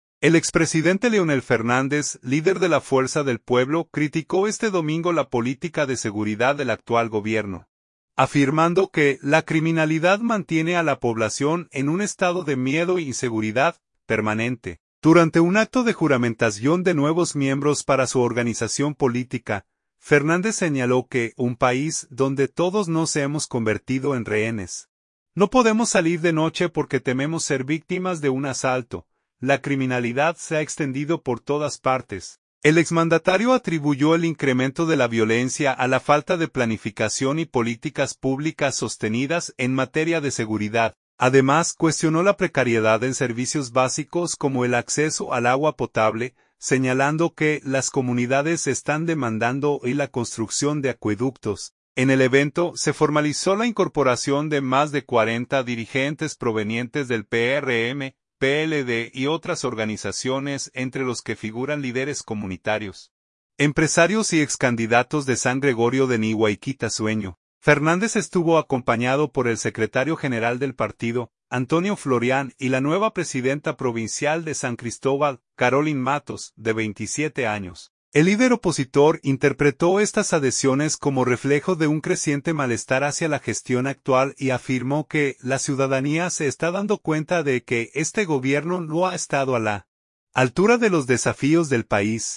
Durante un acto de juramentación de nuevos miembros para su organización política, Fernández señaló que "un país donde todos nos hemos convertido en rehenes, no podemos salir de noche porque tememos ser víctimas de un asalto. La criminalidad se ha extendido por todas partes".